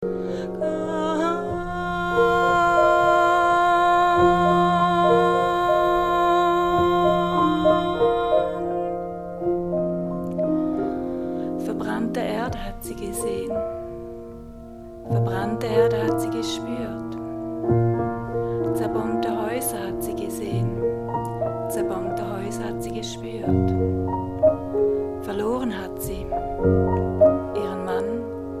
Poesie
Momentum-Aufnahmen